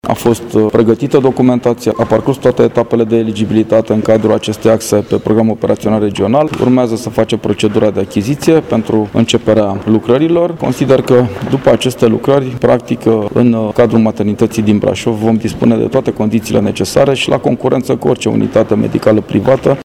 Preşedintele CJ Braşov, Adrian Veştea ne-a spus: